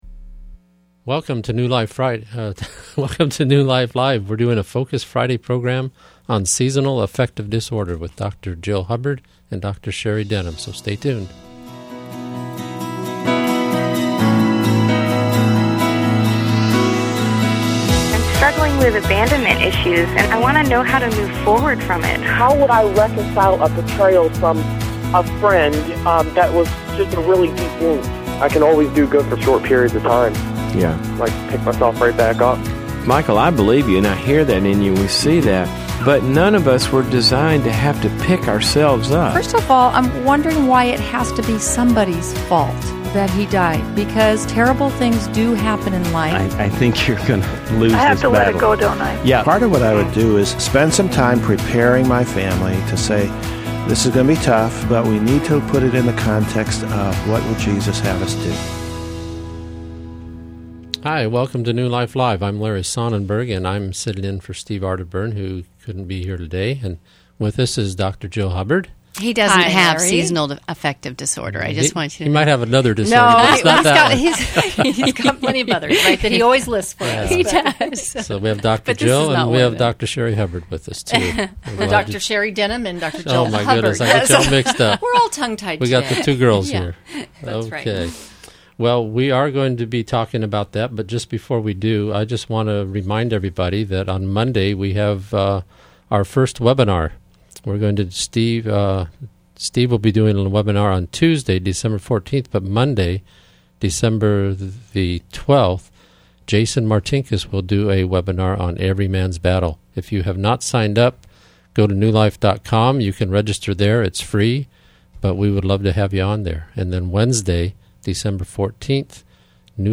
Caller Questions: My son smokes marijuana; should I inform his doctor?